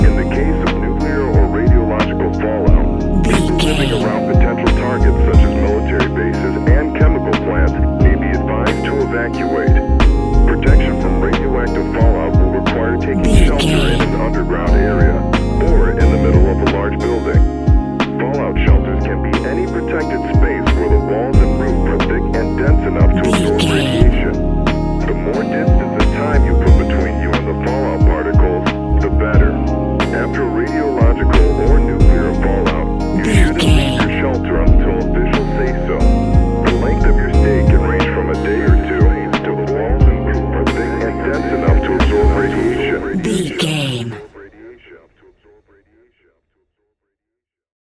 Ionian/Major
C♭
chilled
laid back
Lounge
sparse
new age
chilled electronica
ambient
atmospheric
morphing